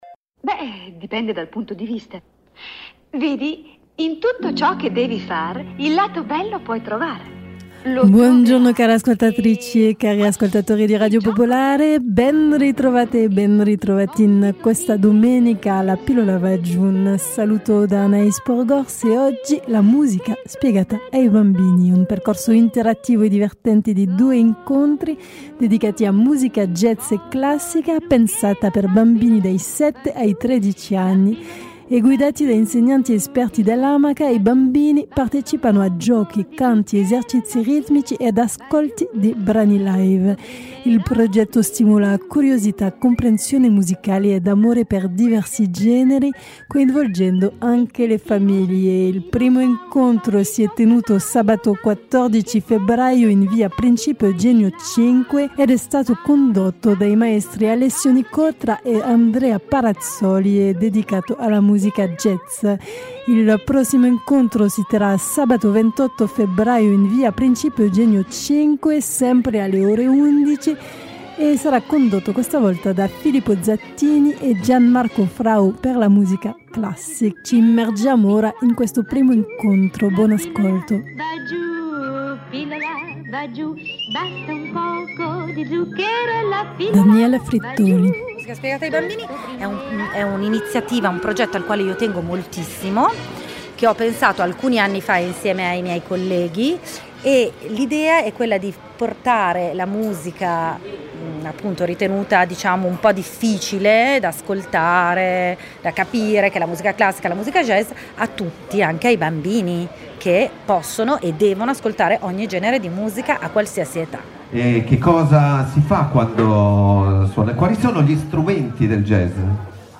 Un percorso interattivo sulla musica jazz per bambini, registrato presso la libreria Feltrinelli, in via Principe Eugenio 5. Evento organizzato dall’associazione Amaca.